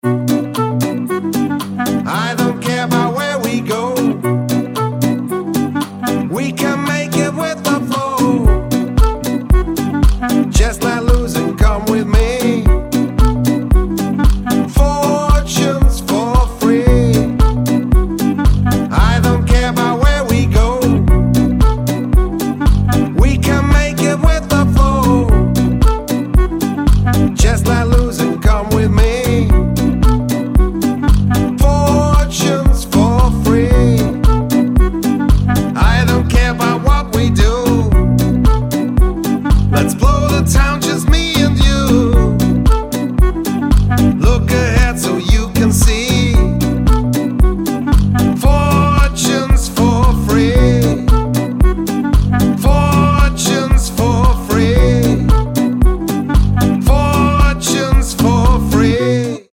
поп
dance
инструментальные
саундтрек
Indie